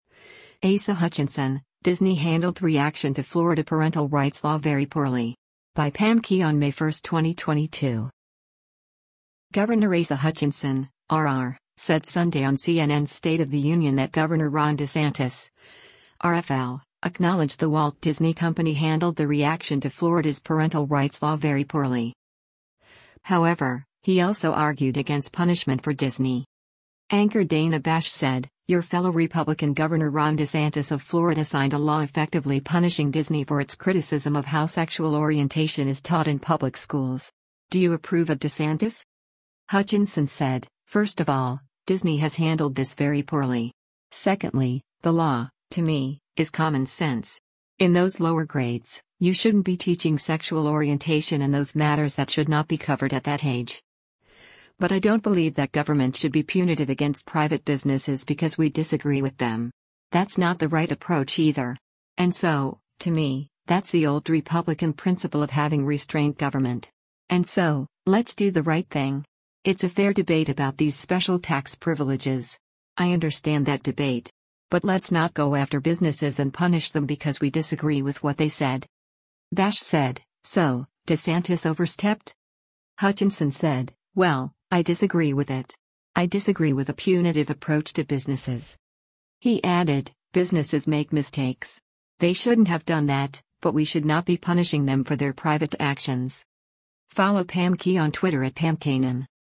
Governor Asa Hutchinson (R-AR) said Sunday on CNN’s “State of the Union” that Gov. Ron DeSantis (R-FL) acknowledged the Walt Disney Company handled their reaction to Florida’s Parental Rights law “very poorly.”